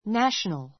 nǽʃənl